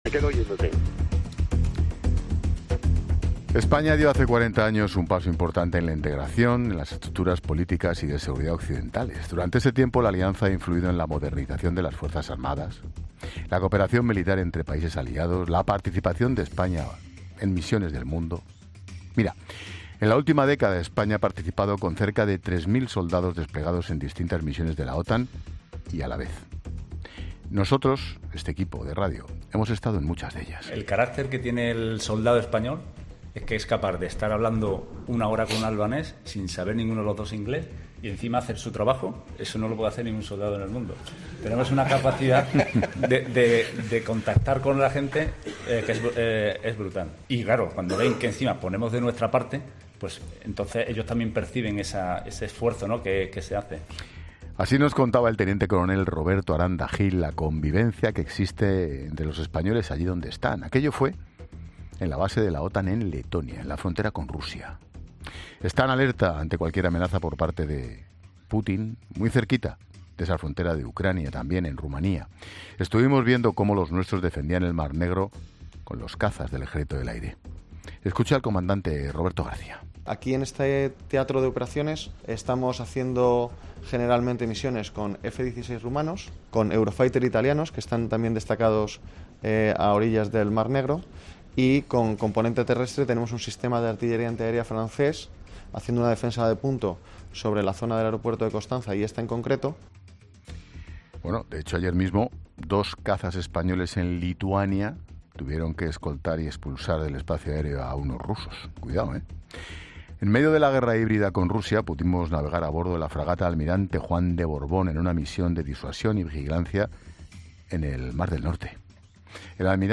Expósito entrevista al almirante Fernando García Sánchez sobre los 40 años en la OTAN